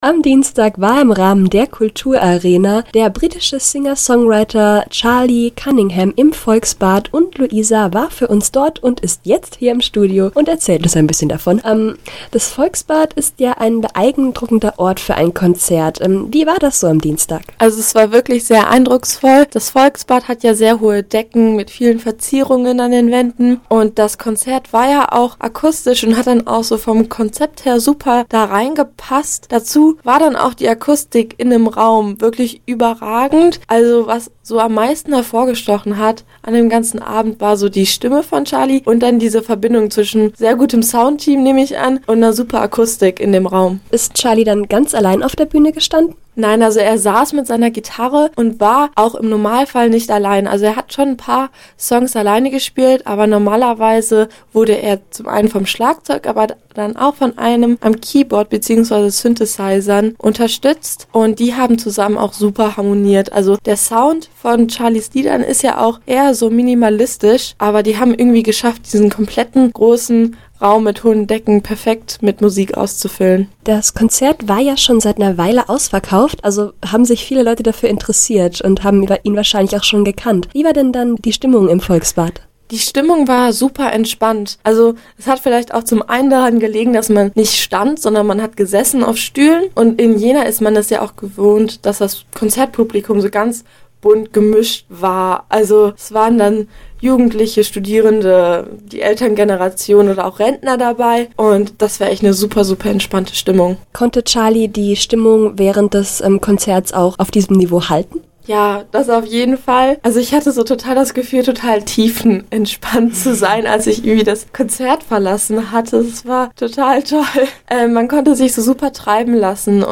Konzertrezension: Charlie Cunningham – Campusradio Jena